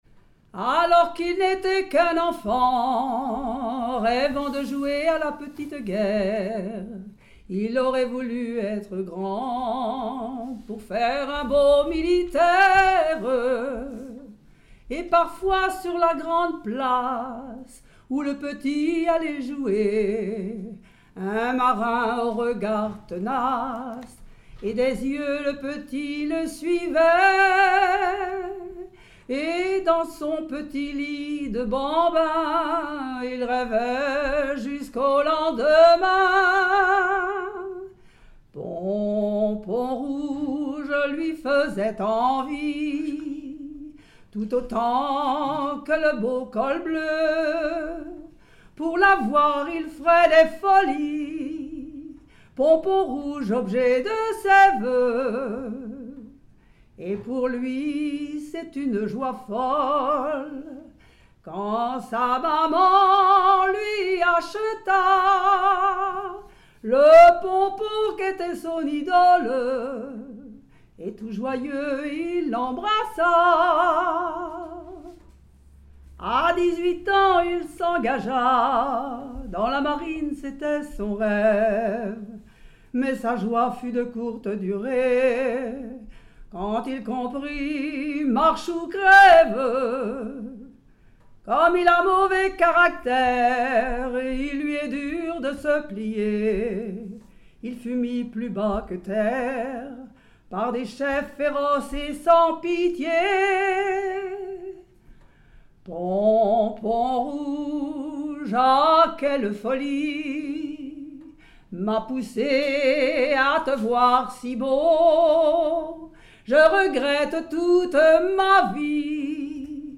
Genre strophique
Chansons
Pièce musicale inédite